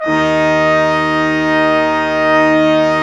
Index of /90_sSampleCDs/Roland LCDP06 Brass Sections/BRS_Quintet/BRS_Quintet % wh